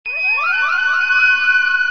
flute nș 7
Flute7.mp3